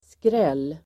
Uttal: [skrel:]